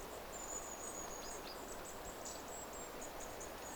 ilmeisesti pyrstötiaisen ääni
ilmeisesti_pyrstotiaisen_aani.mp3